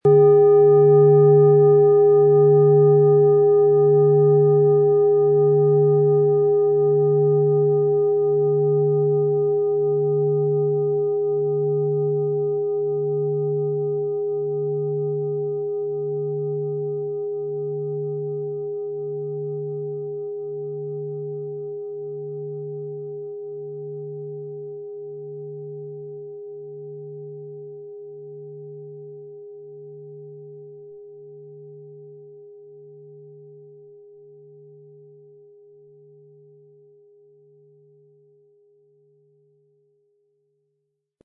Planetenton
Durch die überlieferte Herstellung hat diese Schale vielmehr diesen besonderen Ton und die innere Berührung der liebevollen Handfertigung.
MaterialBronze